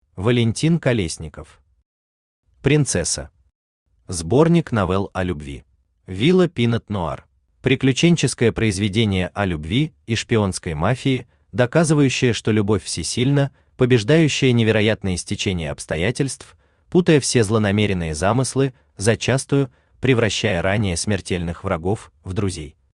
Аудиокнига Принцесса. Сборник новелл о любви | Библиотека аудиокниг
Aудиокнига Принцесса. Сборник новелл о любви Автор Валентин Колесников Читает аудиокнигу Авточтец ЛитРес.